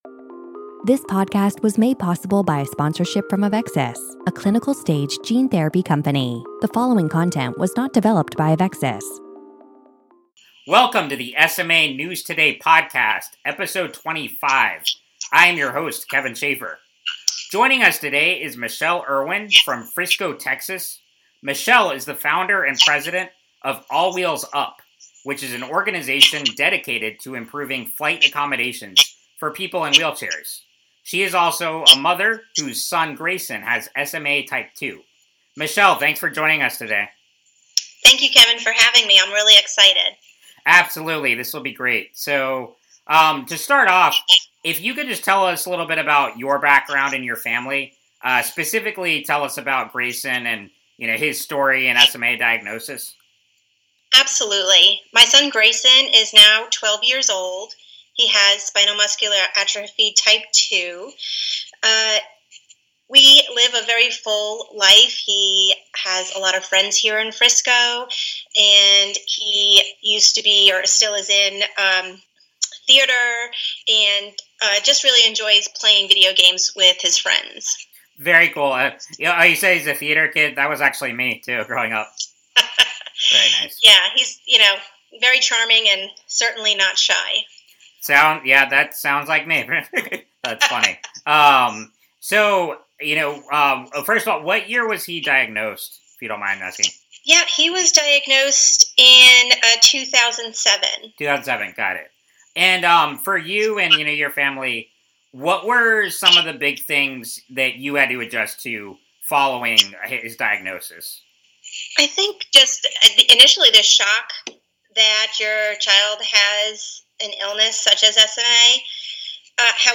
SMA News Today Podcast 25 - Interview